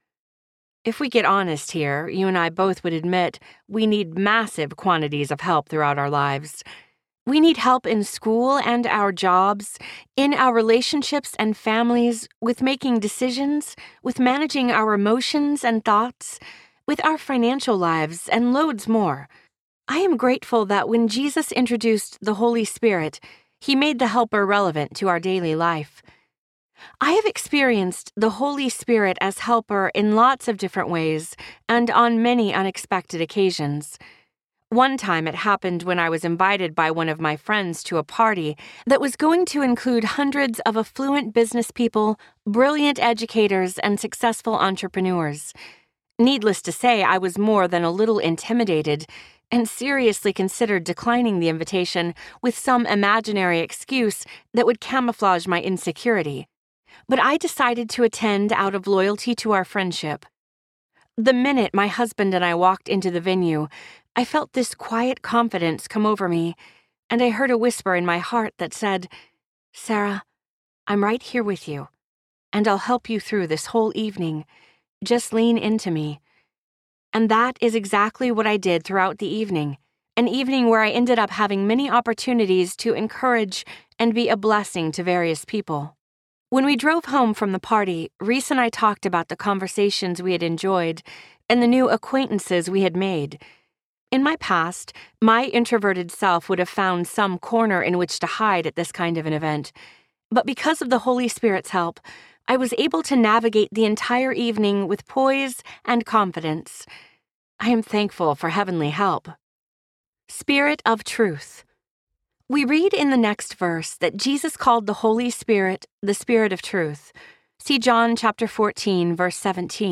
In Step with the Spirit Audiobook
Narrator
6.20 Hrs. – Unabridged